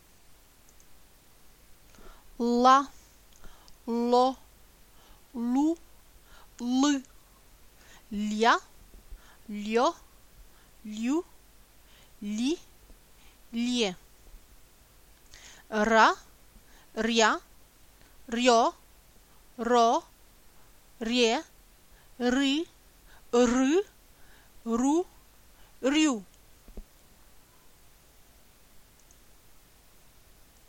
ла, ло, лу, лы- comme le L anglais, bien collé au palais
ля, лё, лю, ли, ле- L comme en français
ра, ря, рё, ро, ре, ри, ры, ру, рю- Р roulé trois fois (une fois de plus qu’en espagnol)